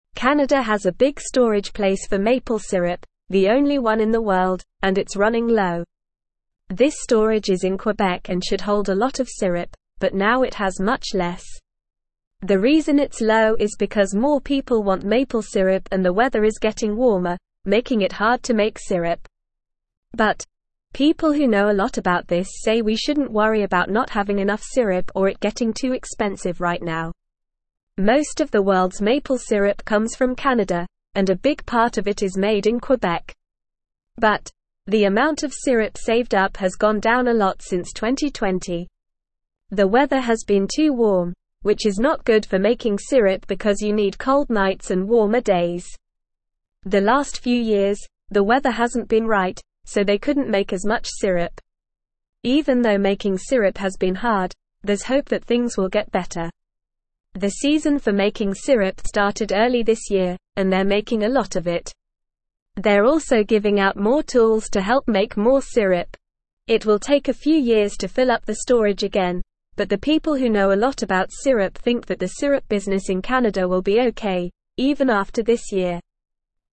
English-Newsroom-Lower-Intermediate-NORMAL-Reading-Canadas-Maple-Syrup-is-Running-Low.mp3